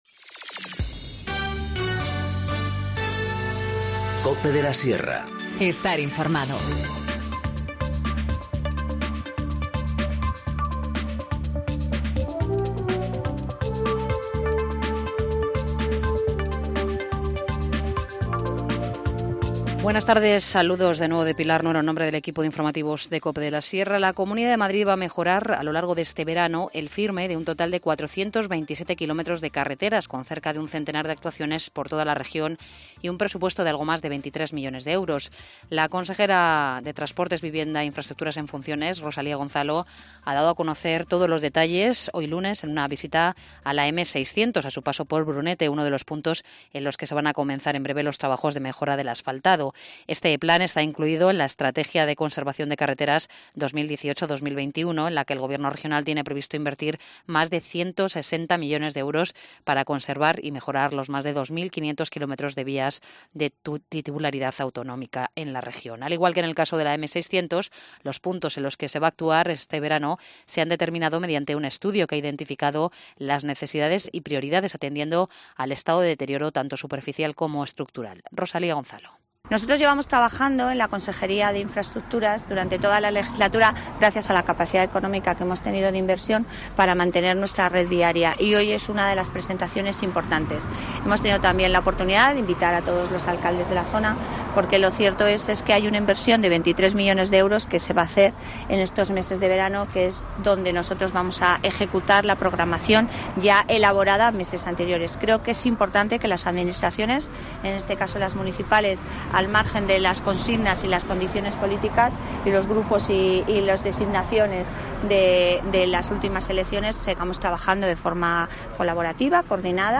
Informativo Mediodía 24 junio 14:50h